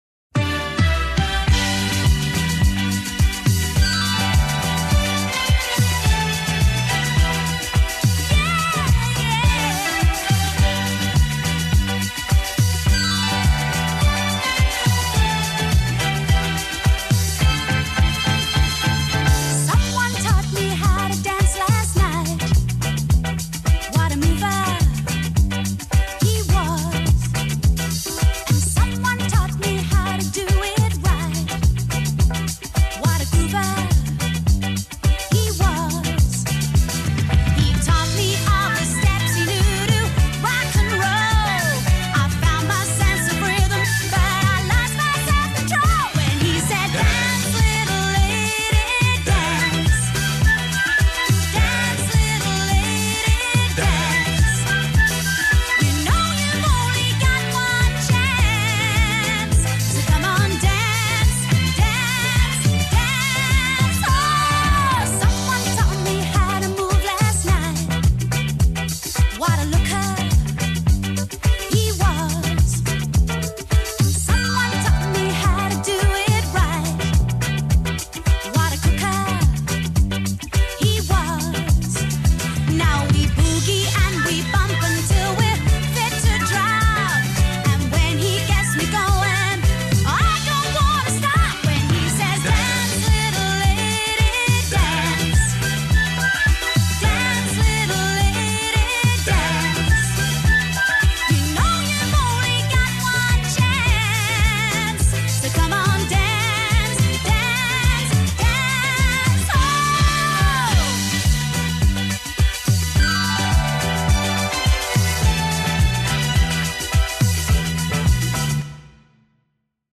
BPM105
MP3 QualityMusic Cut